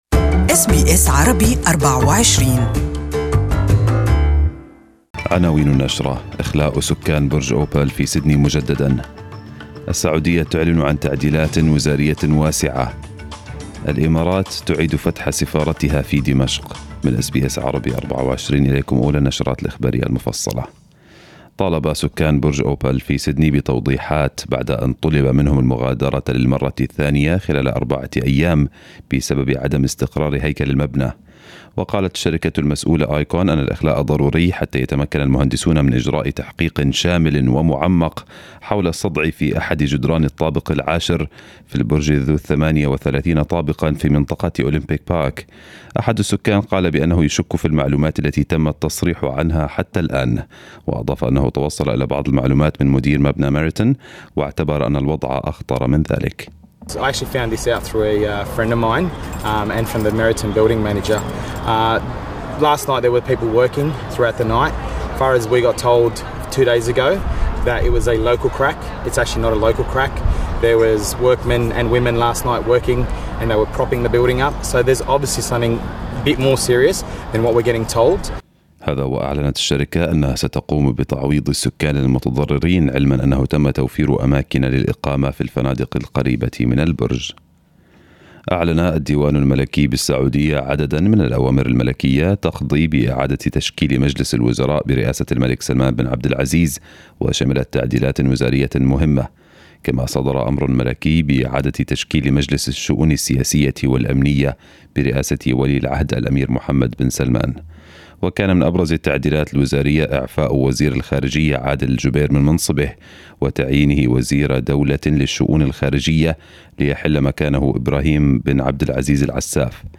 Morning news bulletin in Arabic